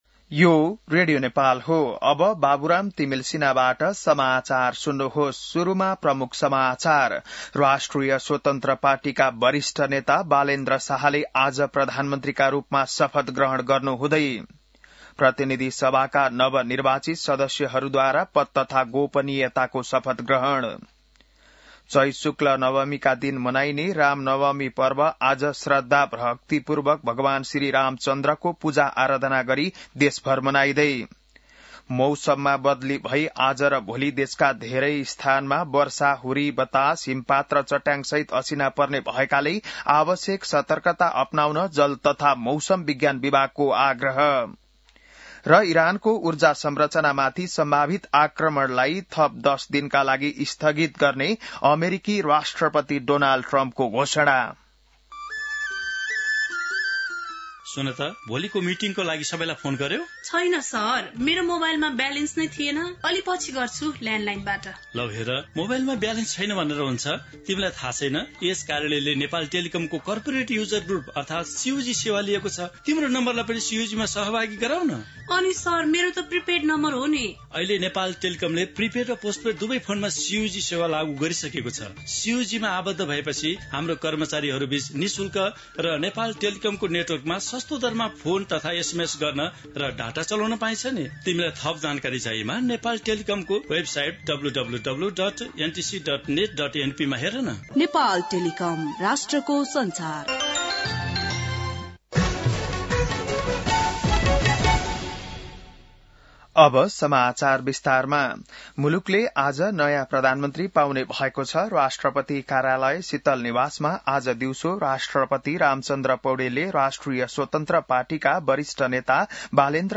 बिहान ७ बजेको नेपाली समाचार : १३ चैत , २०८२